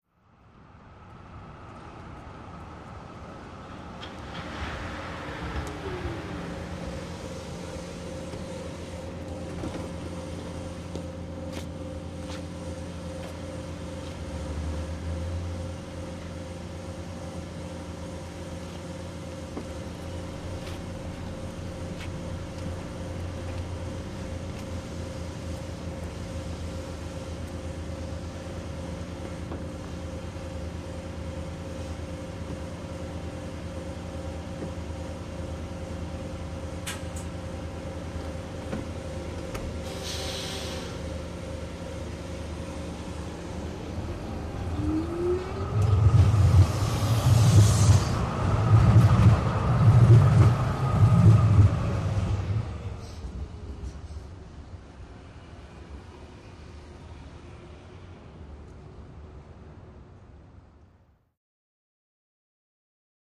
Electric Streetcar, In Medium Speed, Stop Cu, Idle, Door Closes, Away with Throbbing Pulse.